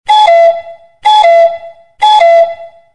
ハト時計（3時）